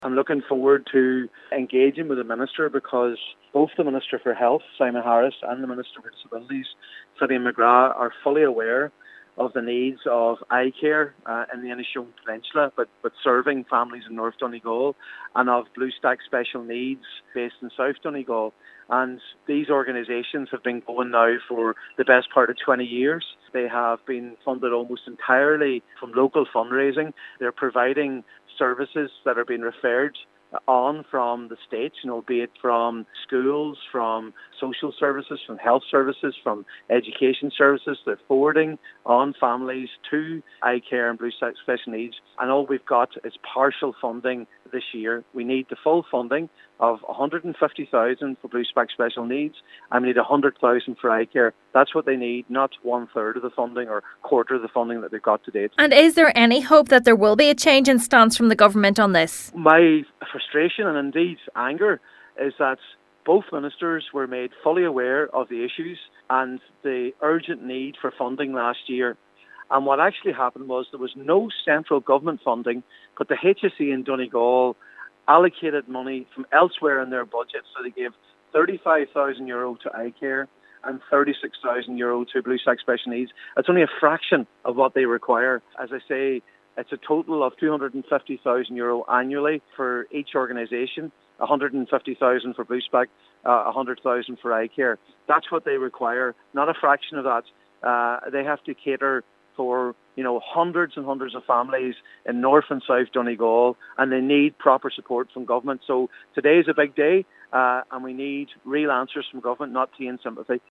Senator Padraig MacLochlainn says he will be demanding answers today: